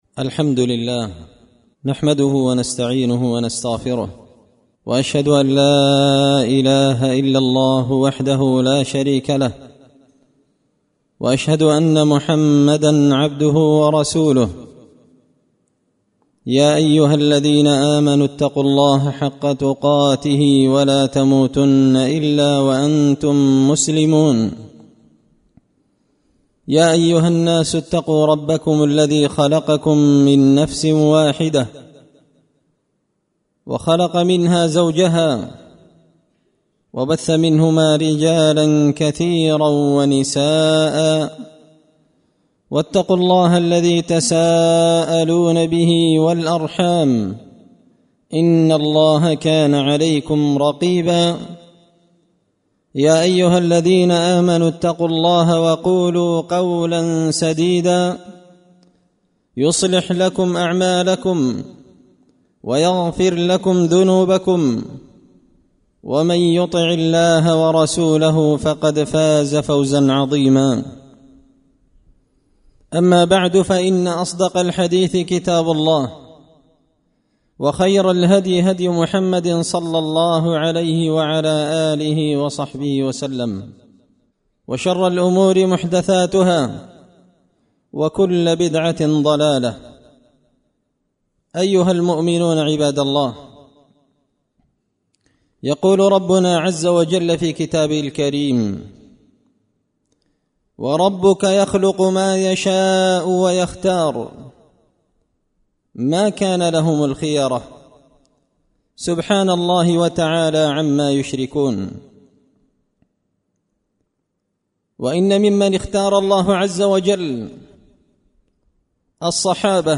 خطبة جمعة بعنوان – فضل الصحابة
دار الحديث بمسجد الفرقان ـ قشن ـ المهرة ـ اليمن